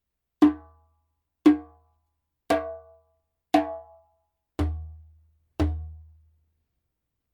Djembe made in Mali at KANGABA workshop
Wood : レンケ Lenke
明るくキレ良いレンケサウンド。
ジャンベ音